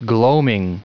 Prononciation du mot gloaming en anglais (fichier audio)